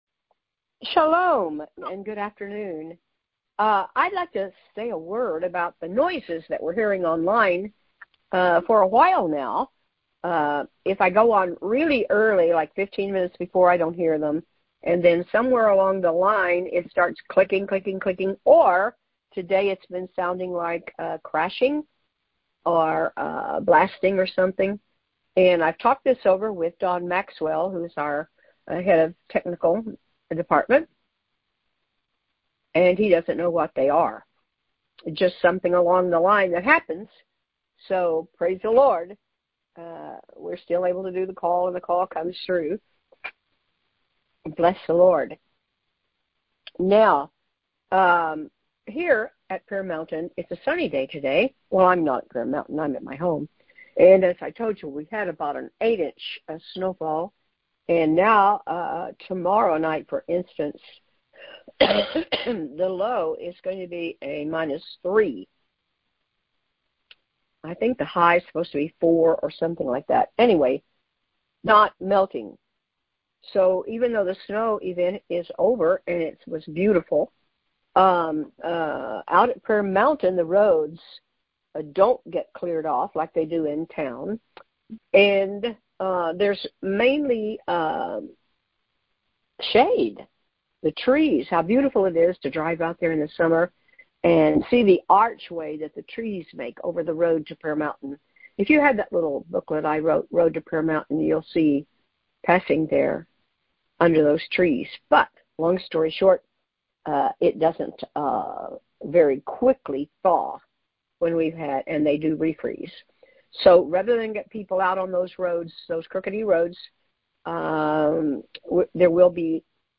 Wednesday Noon Prayer
The audio was recorded via our BBM Phone Cast system.